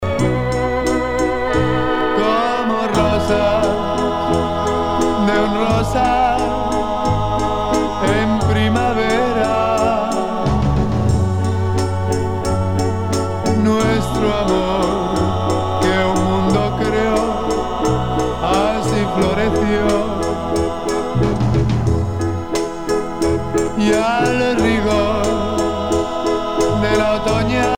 danse : slow fox
Pièce musicale éditée